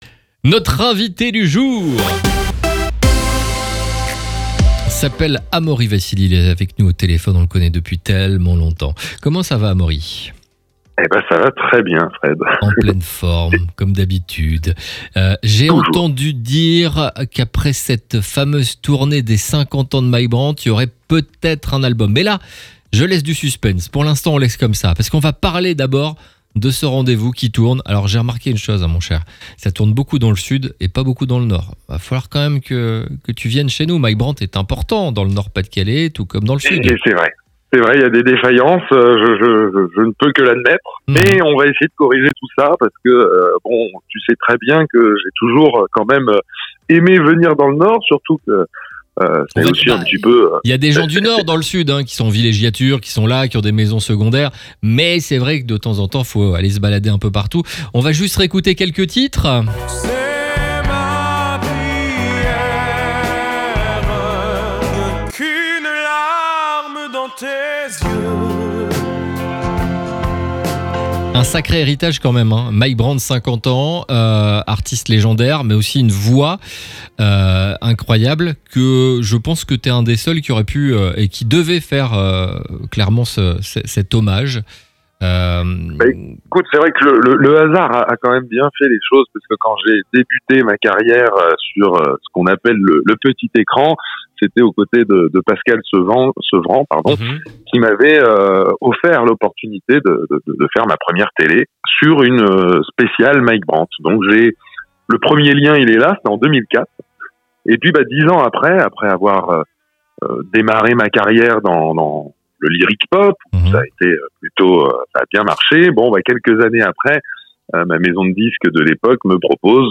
Les interviews exclusifs de RCB Radio